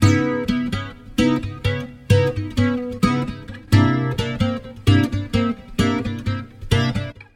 130 Bpm Bossa Guitar 2
描述：Bossa guitar.
标签： 130 bpm Jazz Loops Guitar Acoustic Loops 1.24 MB wav Key : D
声道立体声